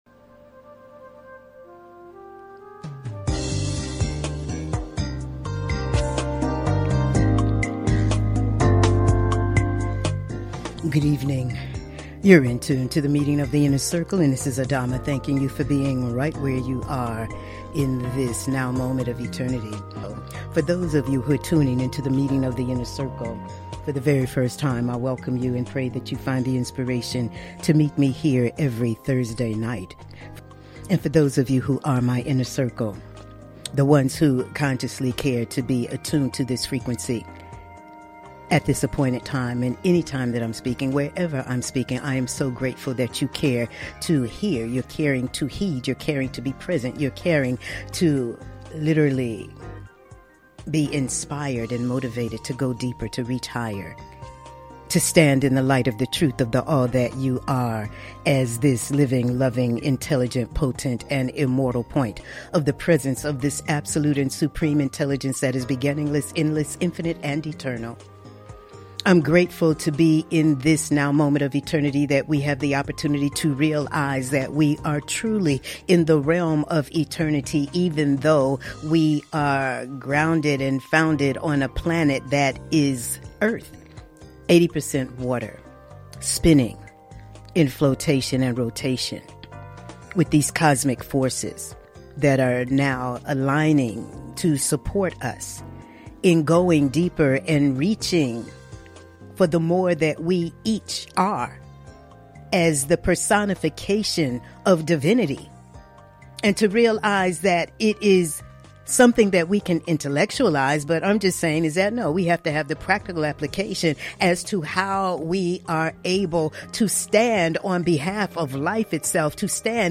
Talk Show Episode
Monologues